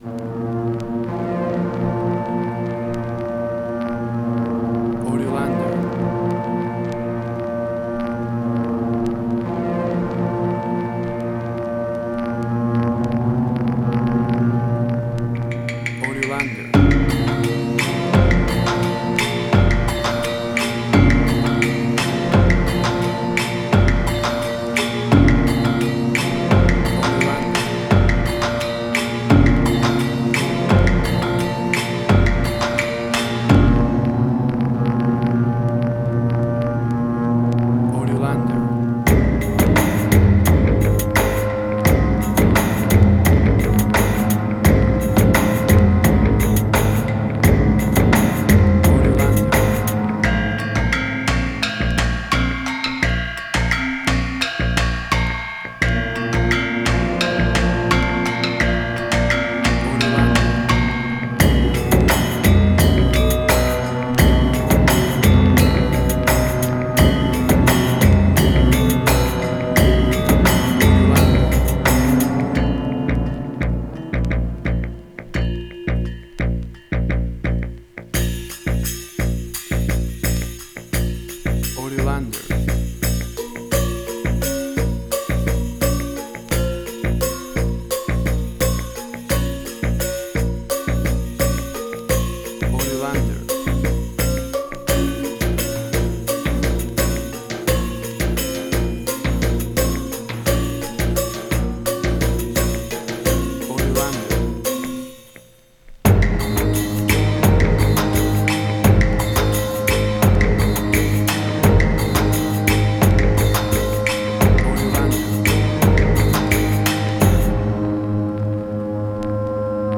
Gamelan Ethnic instrumental.
Tempo (BPM): 86